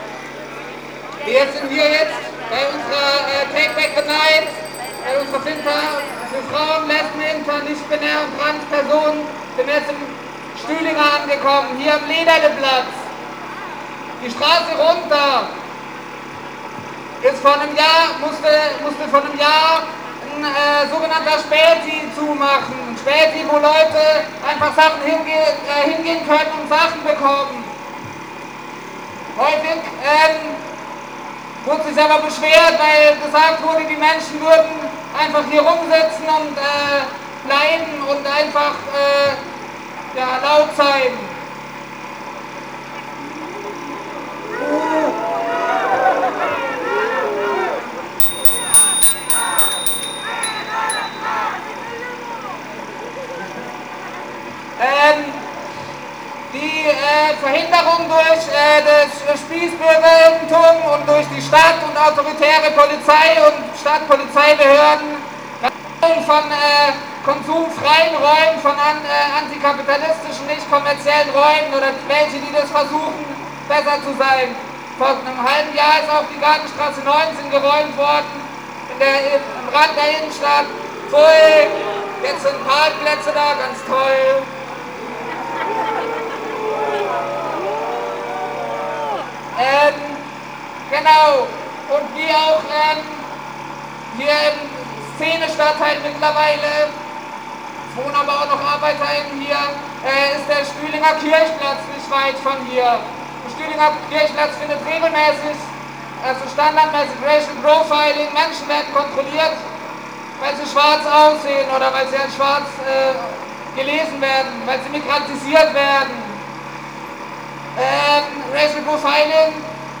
Vor dem Martinstor, am Freiburger Stadtgarten, am Lederleplatz im Stühlinger sowie nahe der Wilhelmstraße wurden Reden gehalten:
Lederleplatz